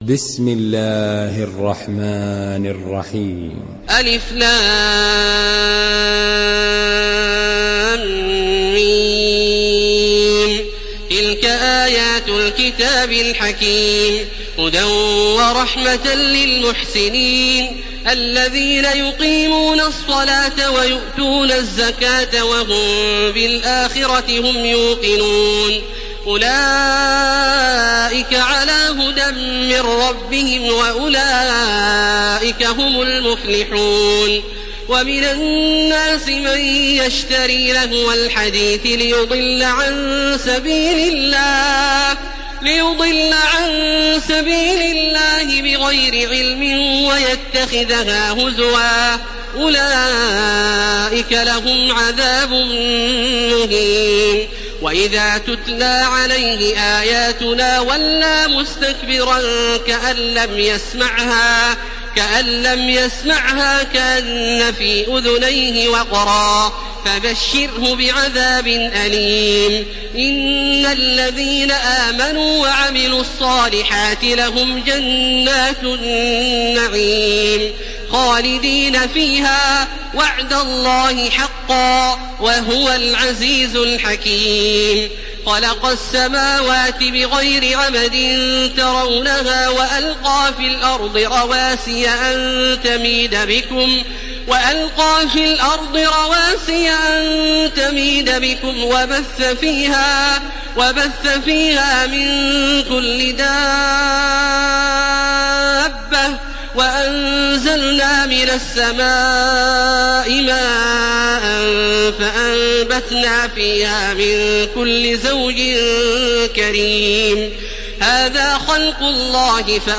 Surah Luqman MP3 by Makkah Taraweeh 1431 in Hafs An Asim narration.
Murattal